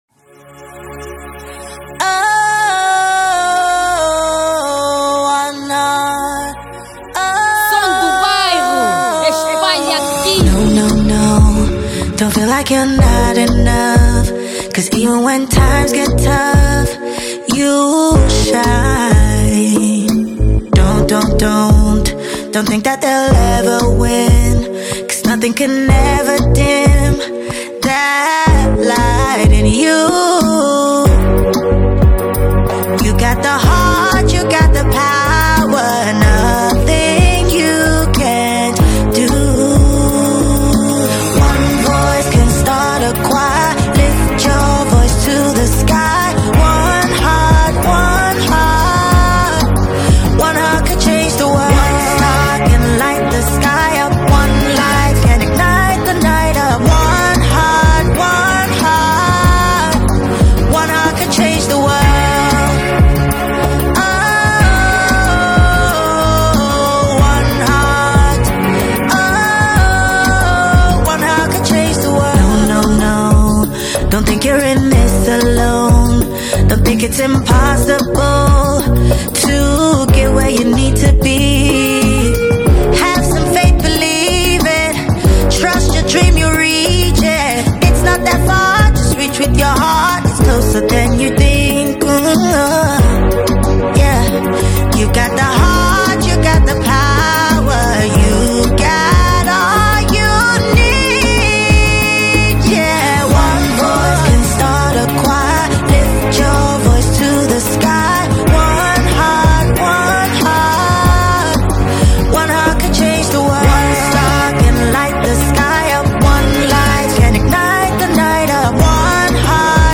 Genero: Afro Beat